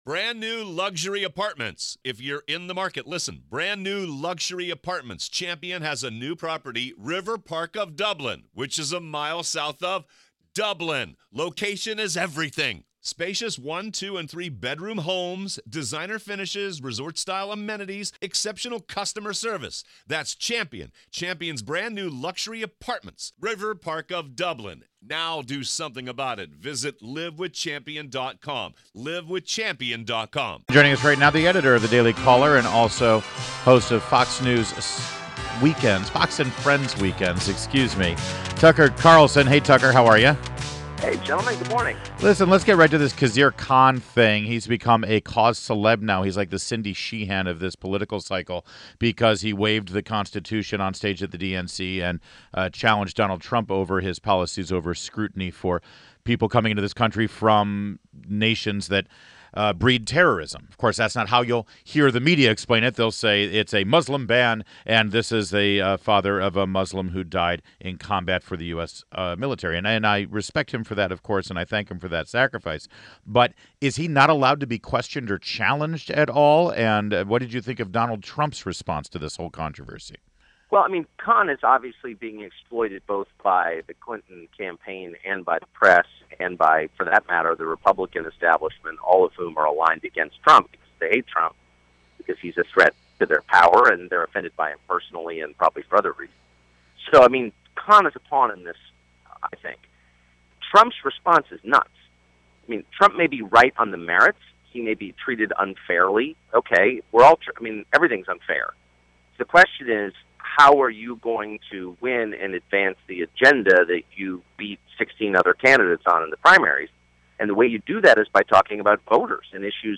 WMAL Interview - Tucker Carlson - 8.1.16